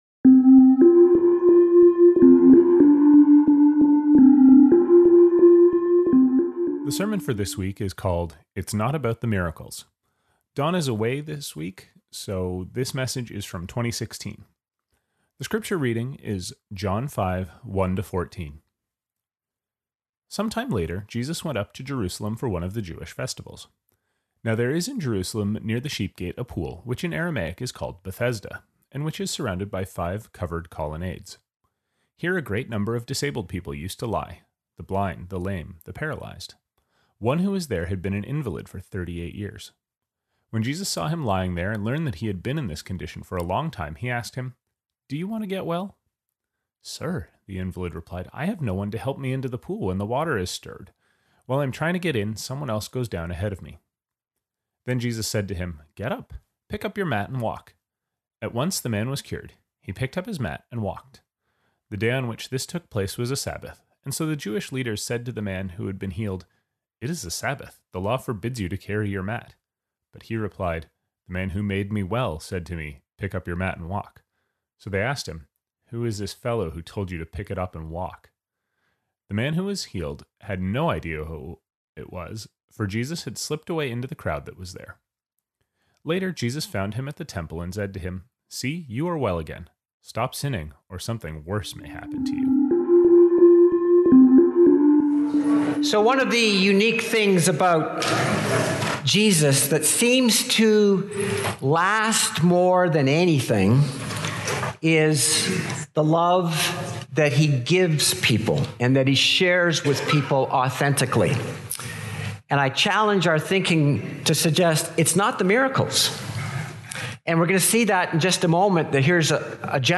It was originally preached in August 2016.